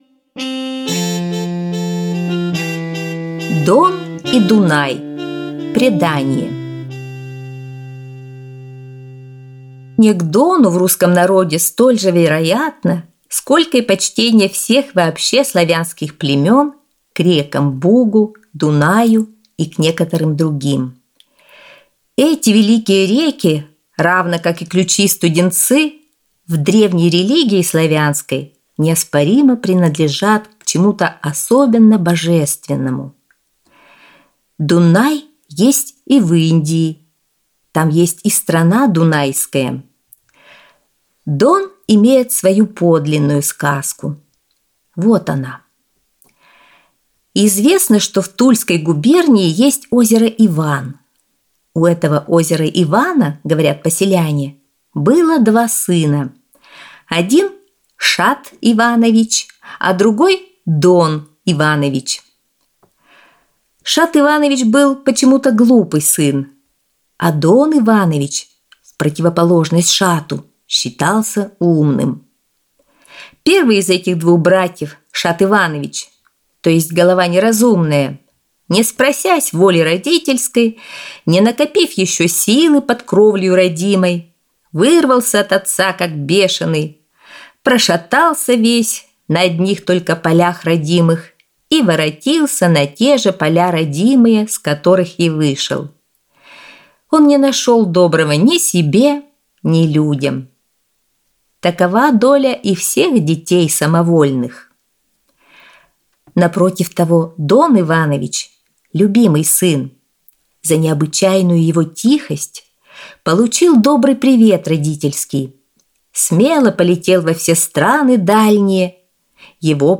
Аудиосказка «Дон и Дунай»